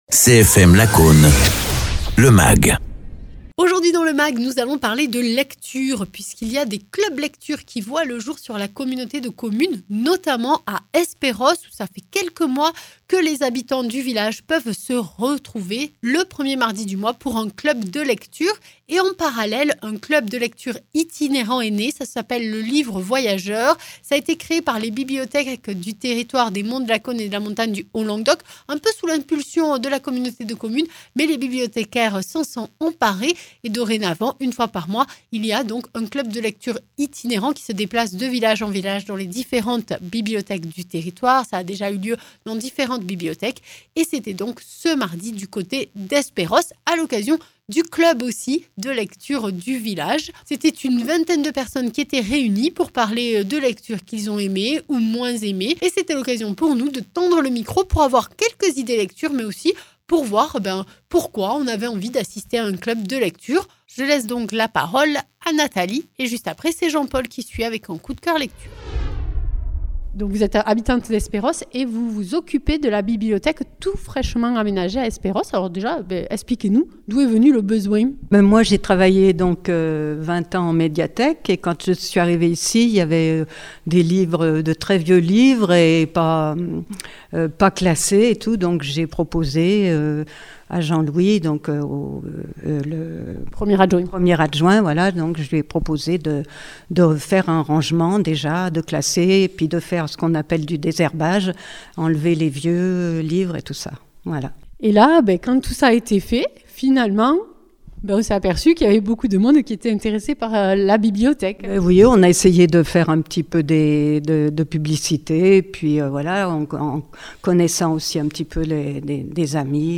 Nous sommes allés à leur rencontre à Espérausses au mois de mars, une vingtaine de personnes étaient présentes autour de s lectures des uns et des autres et de la présence d’un scénariste de BD.
Interviews